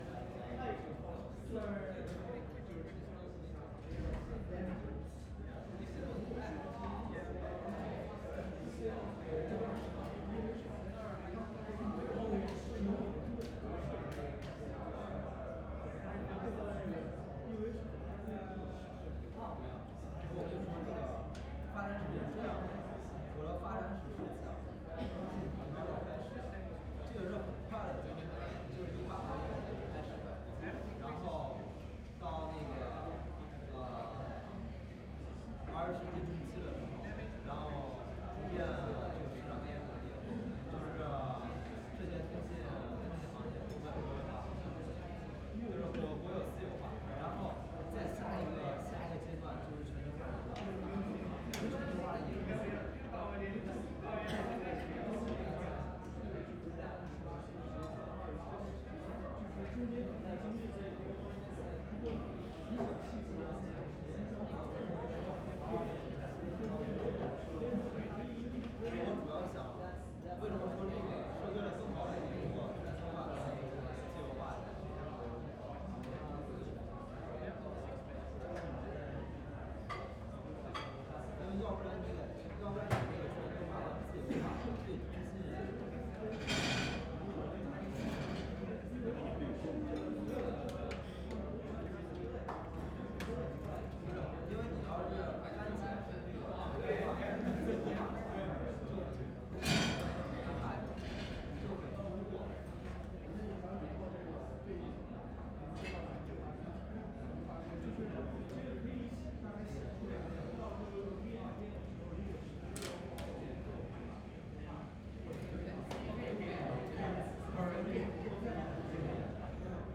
Ambience, School, University Of Melbourne, Walla, Male Students Talking, School Cafeteria 01 SND40010.wav
test cafeteria ambience 2025-11-06 23:36:43 +02:00 76 MiB